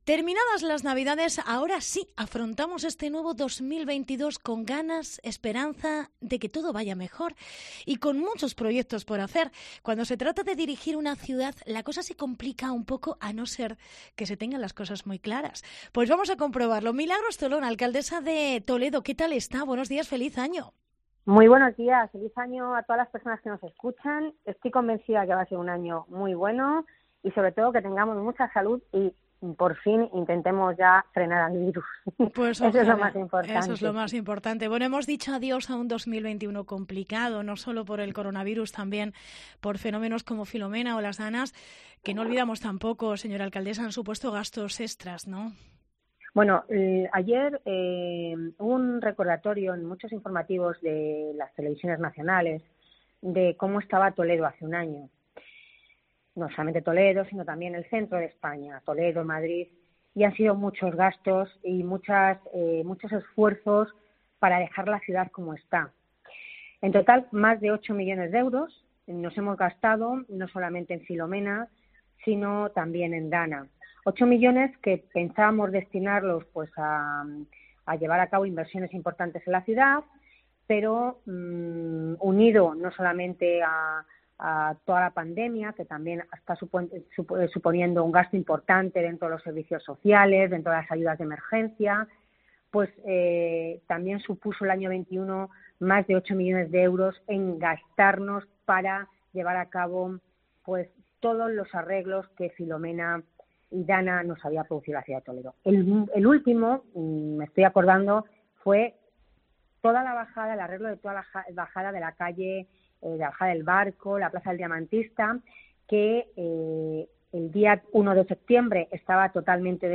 Entrevista Milagros Tolón proyectos 2022